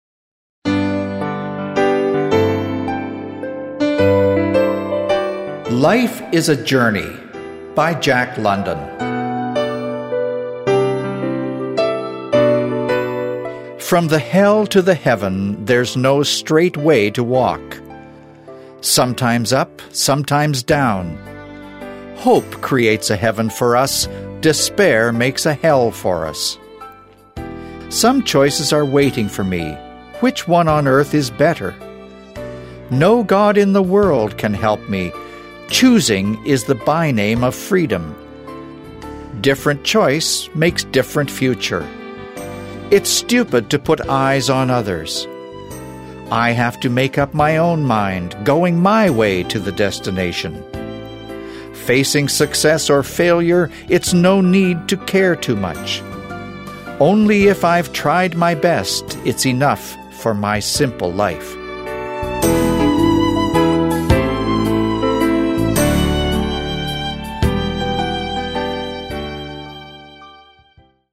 推荐级别：美音 适合背诵